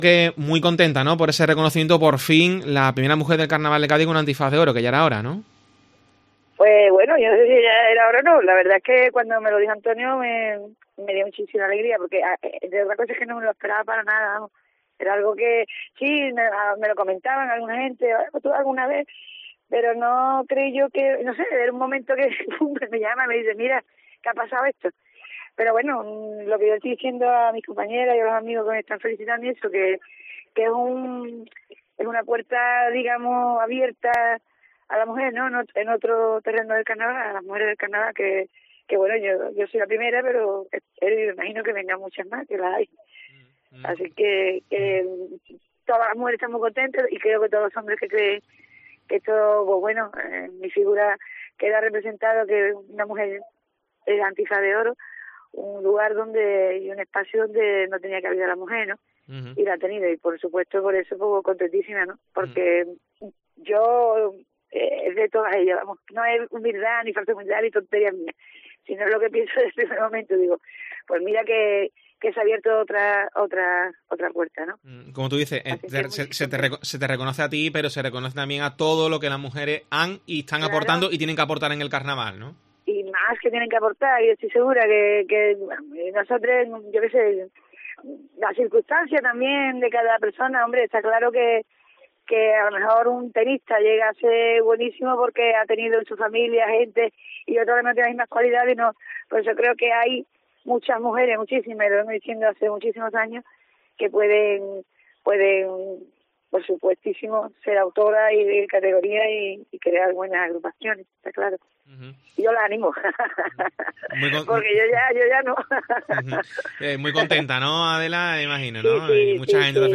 en los micrófonos de COPE Cádiz